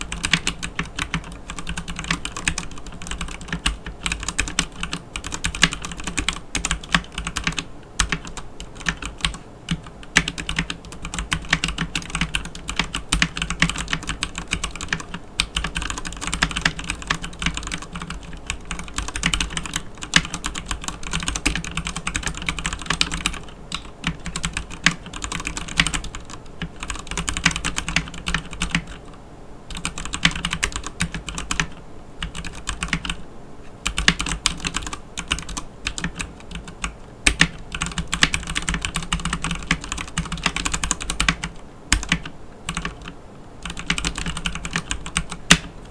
The sample I have here is using the Cherry MX Red switch, but there will be other types of Cherry MX RGB switches available for the Ducky One 2 RGB as well.
Typing Sound Test
The PBT keycaps on the Ducky One 2 RGB TKL has a distinctive solid clack when you bottom a key, which is much more satisfying as compared to the thinner ABS keycaps you’ll find on the budget mechanical keyboards.
Ducky-One-2-RGB-TKL-Type-Sound.ogg